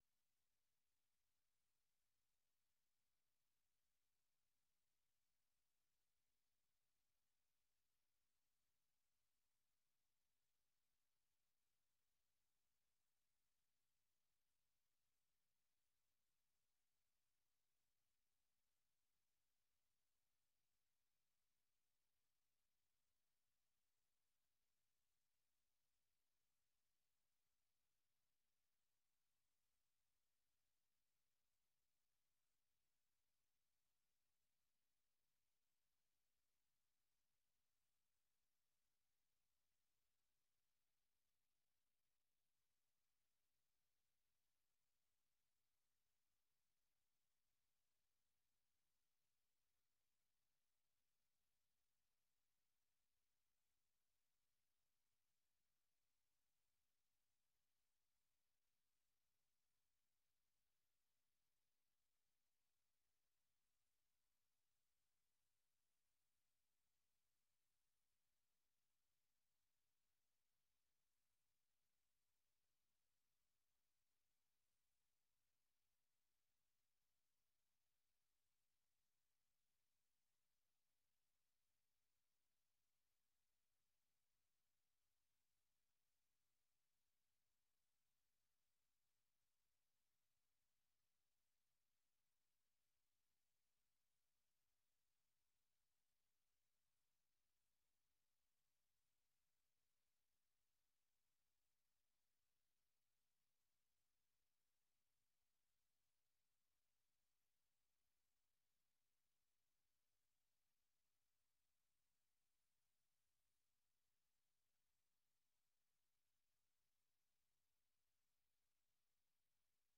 Un noticiero con información diaria de Estados Unidos y el mundo.